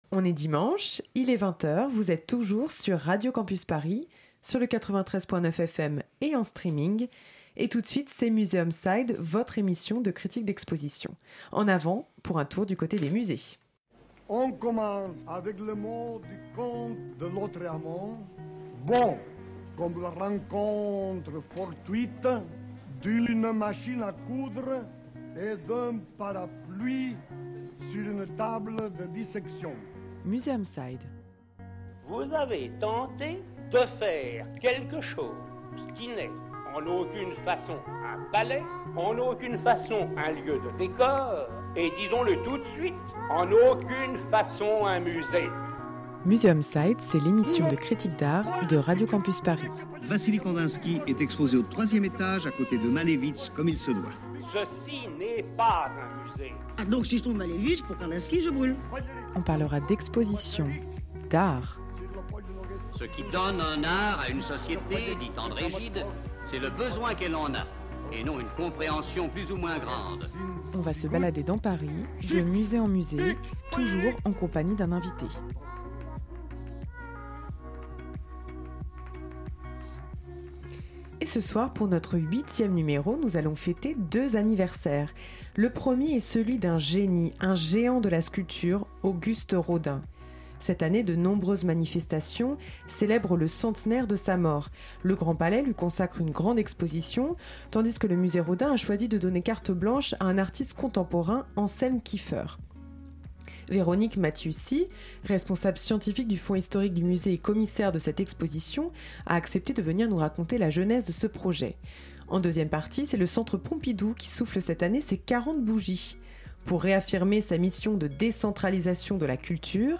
Le principe : deux expositions à l'affiche dans de grands musées parisiens sur lesquelles nous débattrons avec à chaque fois un invité spécialiste qui nous donnera des clés de compréhension.